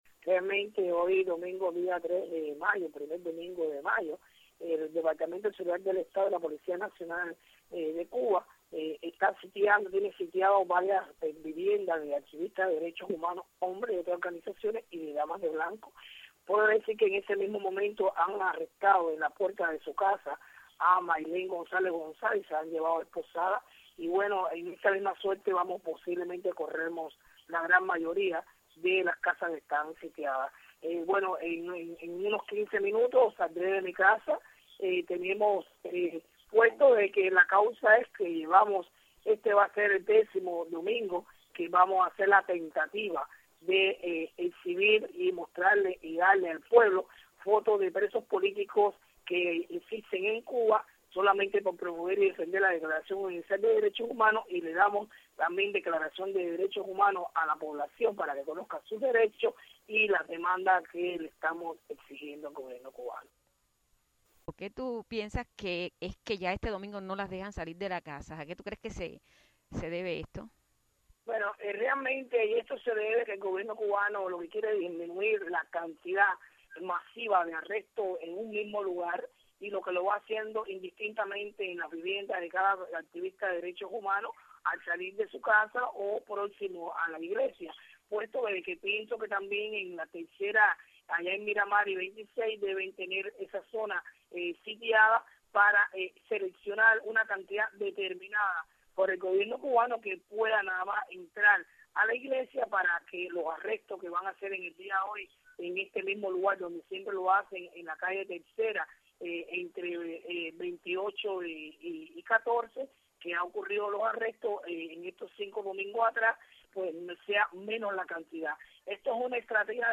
Declaraciones de Berta Soler minutos antes de su arresto hoy en La Habana